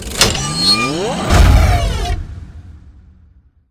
bladeson.ogg